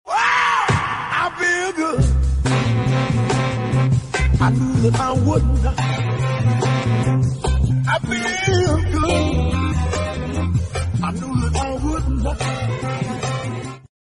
AI Singing Kitty